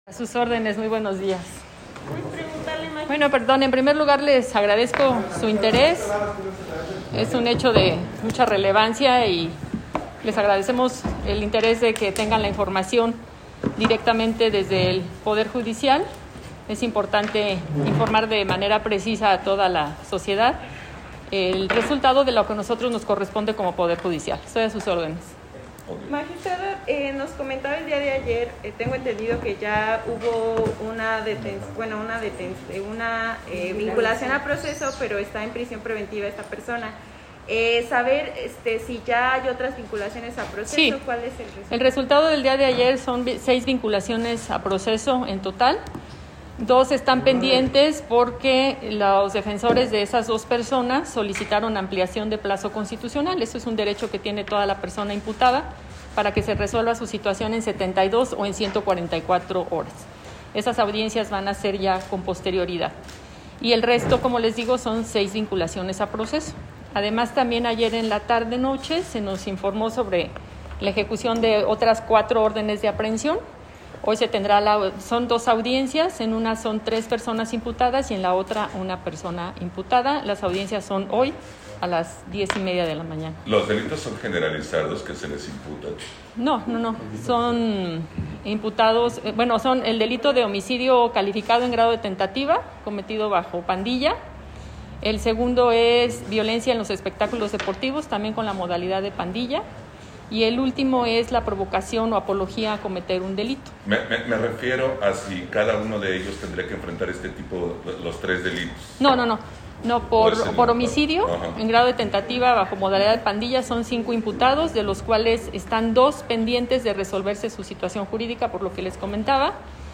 Rueda de prensa TSJ